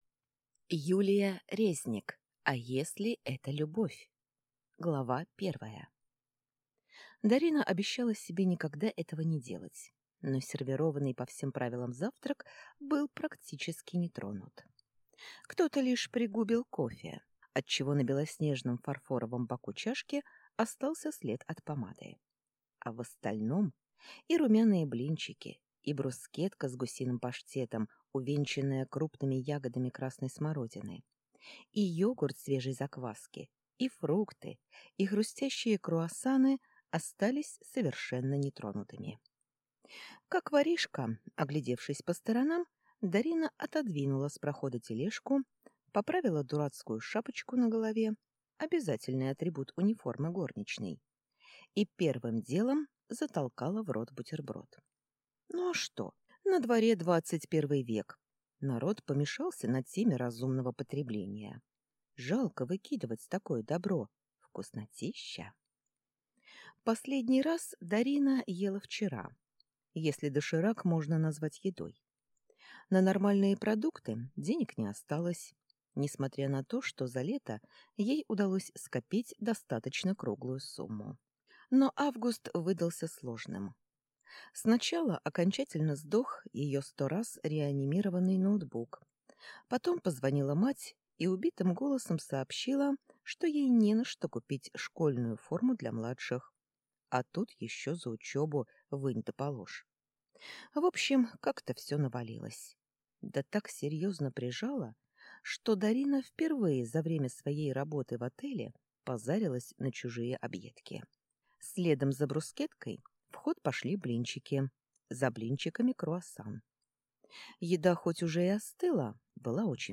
Аудиокнига А если это любовь?